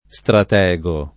stratego [ S trat $g o ] s. m. (stor.); pl. -ghi